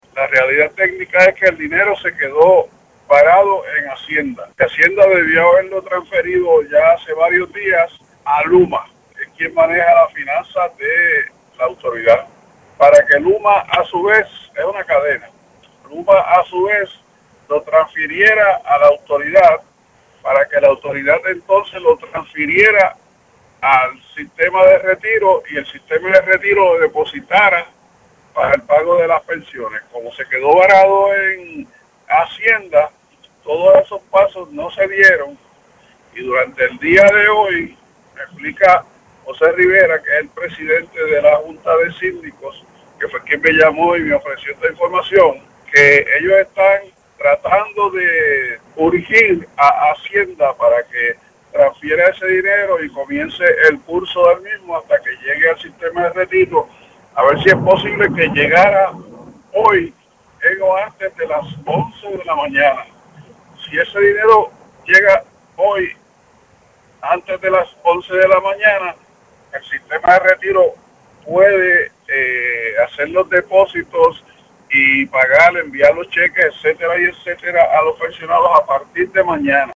en entrevista con este medio.